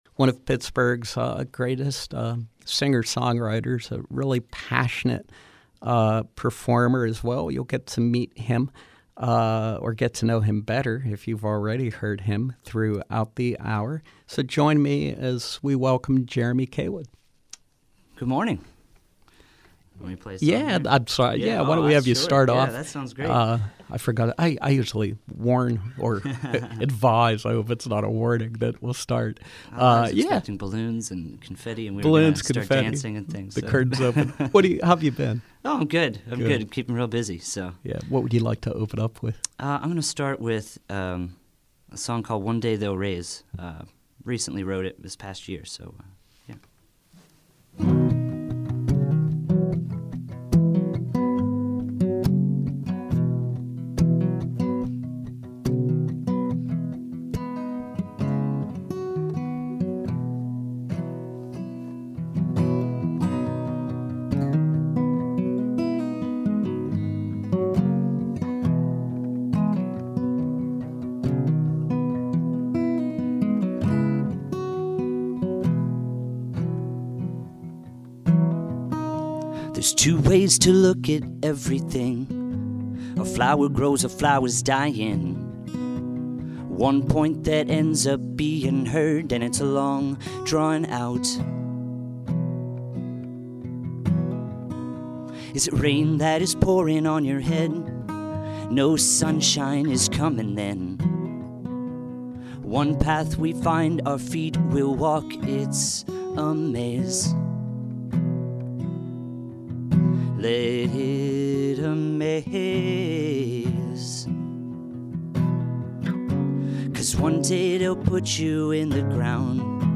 acoustic Sunday brunch